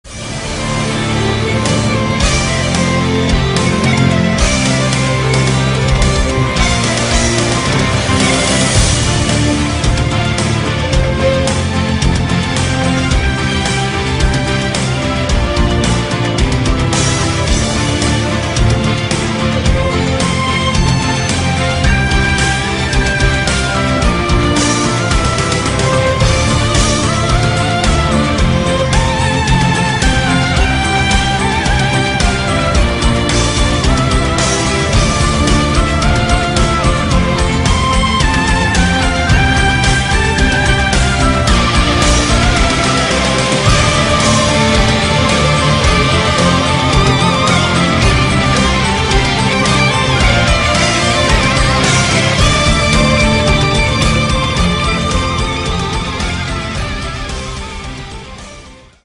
électronique ,Films